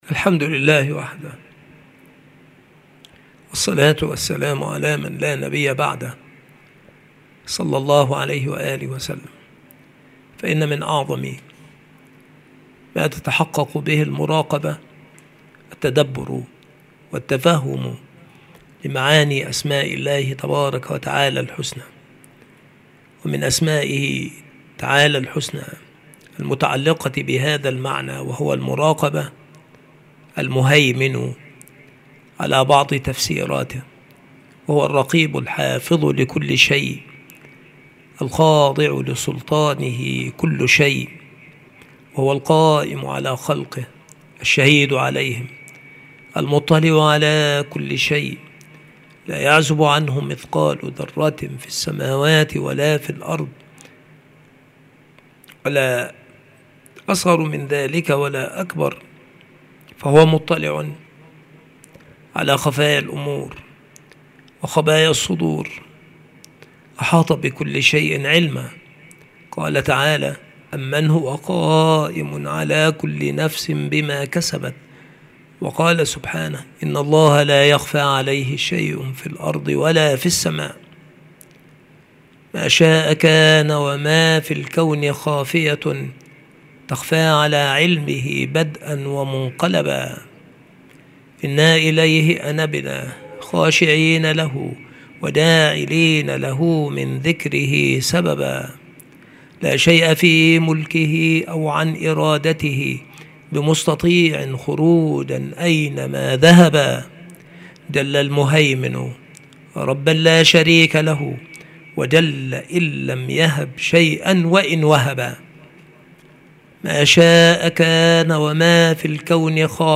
المحاضرة
مكان إلقاء هذه المحاضرة المكتبة - سبك الأحد - أشمون - محافظة المنوفية - مصر عناصر المحاضرة : اسمه تعالى ( المهيمن ). اسمه تعالى ( القريب ). قربه تعالى نوعان. مما تتحقق به المراقبة. حقيقة مشهد المراقبة.